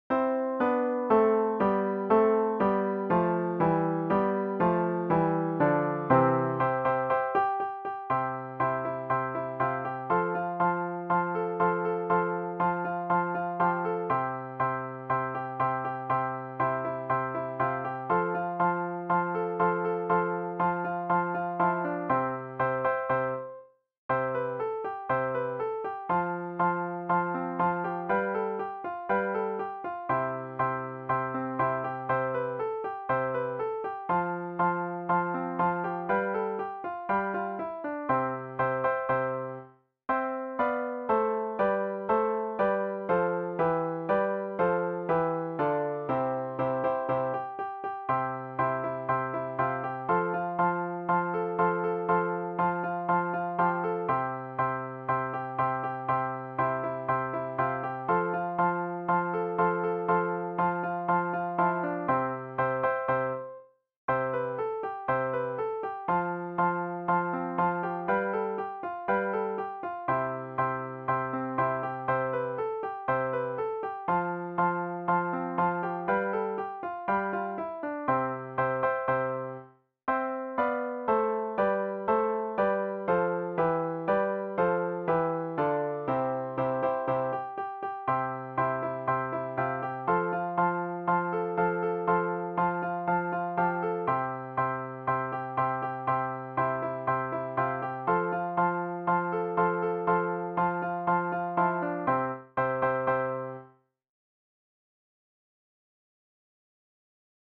vi servirà  come base per cantare   o suonare